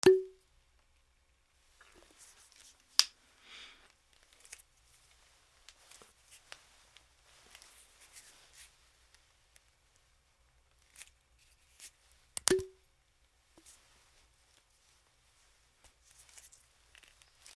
ワインのコルク栓を抜く 2